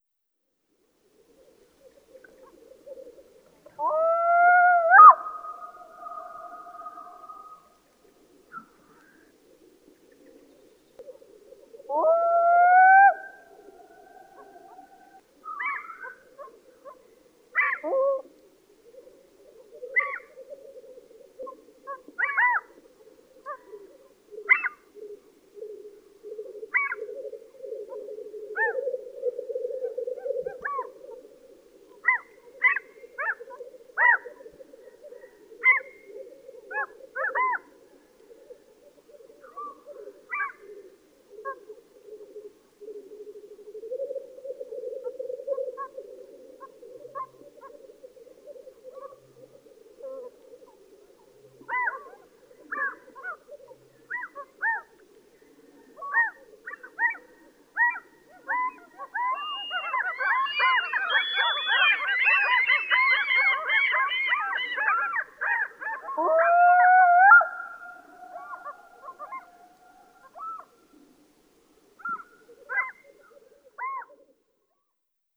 • black throated loon bird echo.wav
black_throated_loon_bird_echo-2_Jb7.wav